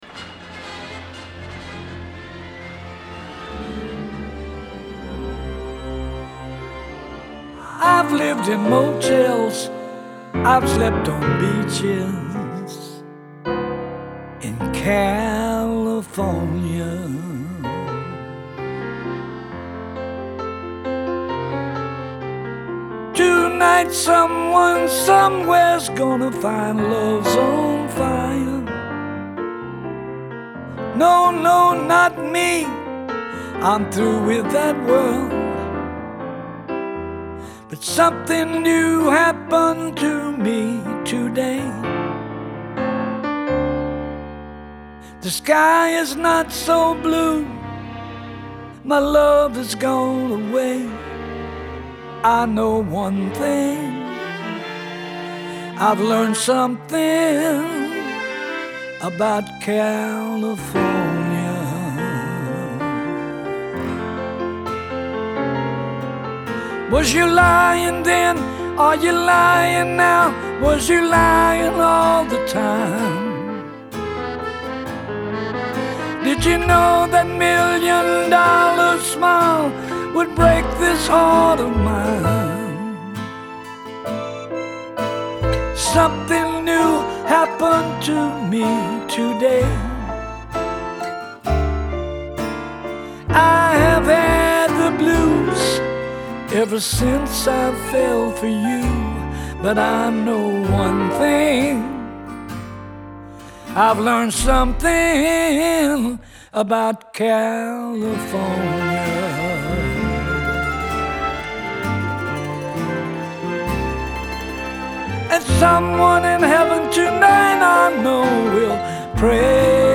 Genre : Blues